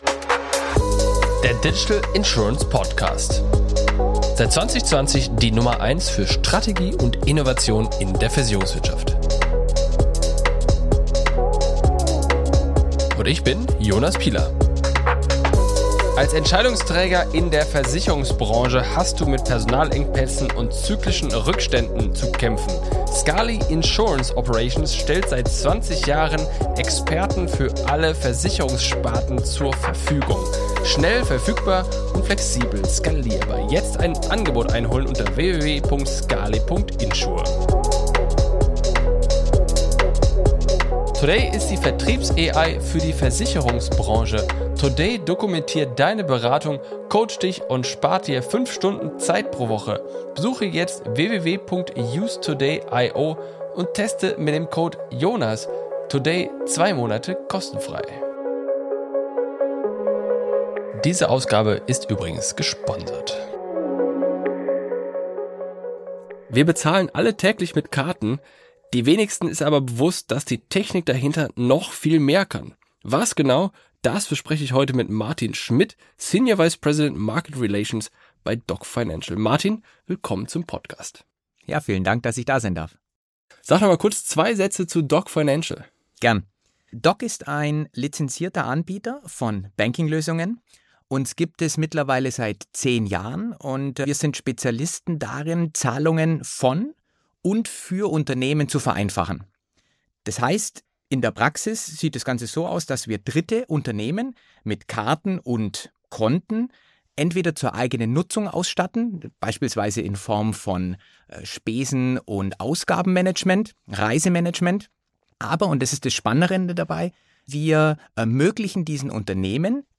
In dieser Folge des Digital Insurance Podcast spreche ich mit